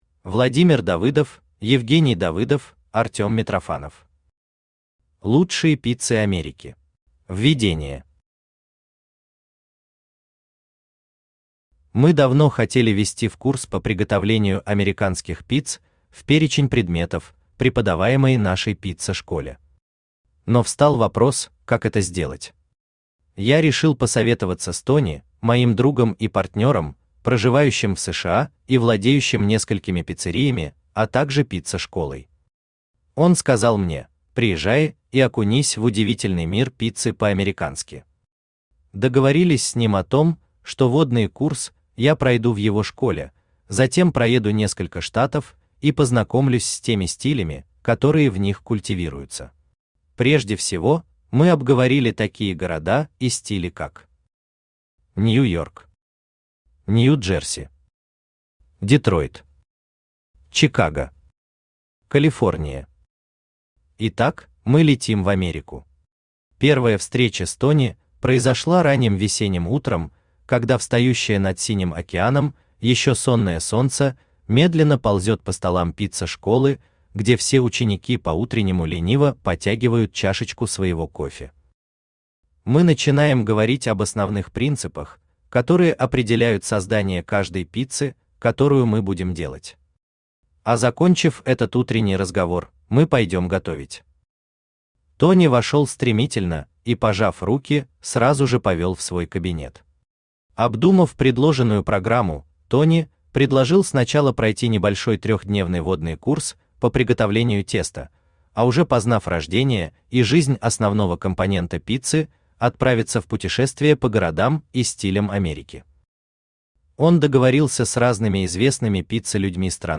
Аудиокнига Лучшие пиццы Америки | Библиотека аудиокниг
Aудиокнига Лучшие пиццы Америки Автор Владимир Давыдов Читает аудиокнигу Авточтец ЛитРес.